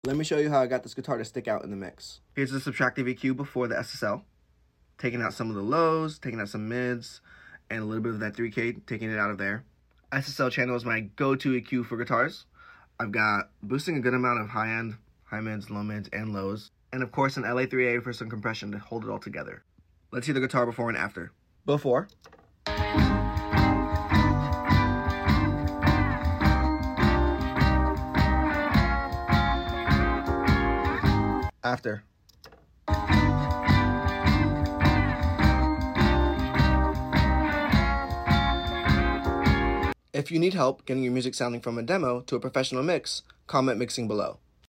Getting electric guitar to stick sound effects free download